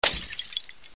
Bicchiere distrutto
Suono di bicchiere o piccolo oggetto in vetro che si infrange sul pavimento.
SHATTER2.mp3